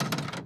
Updated tool SFX
tool_bonk_v1.wav